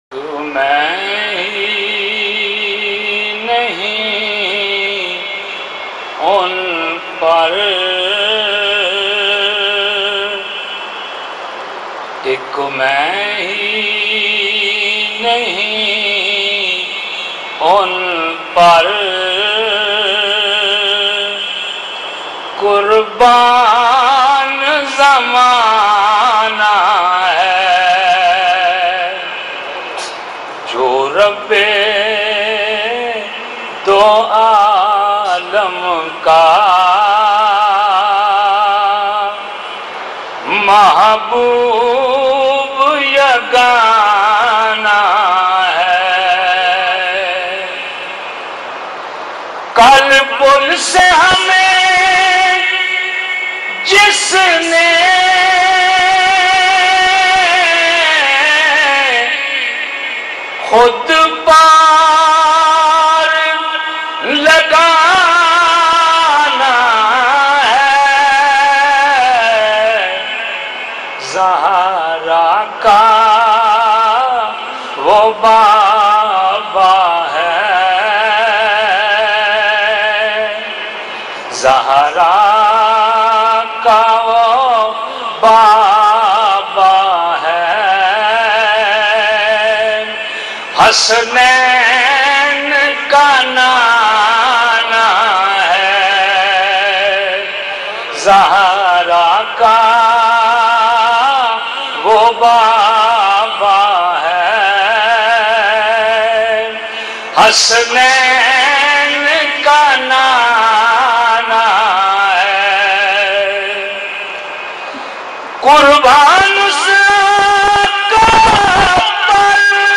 Heart Touching Naat